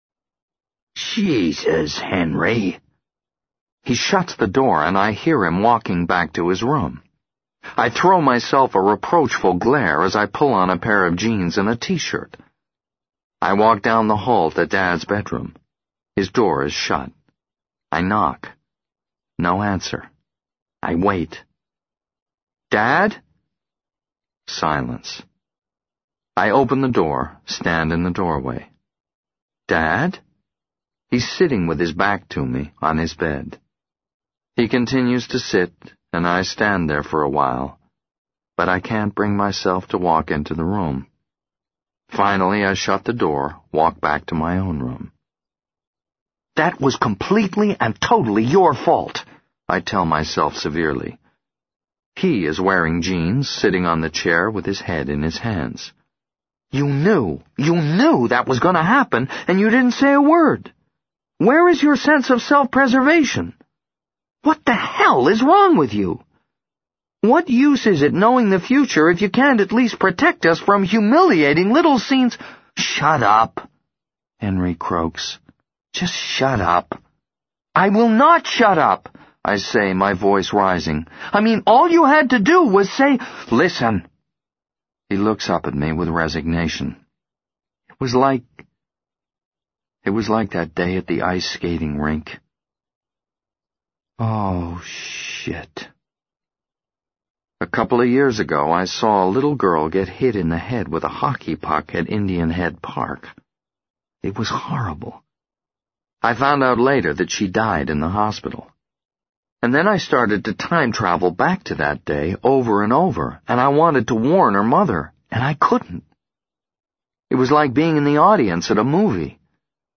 在线英语听力室【时间旅行者的妻子】48的听力文件下载,时间旅行者的妻子—双语有声读物—英语听力—听力教程—在线英语听力室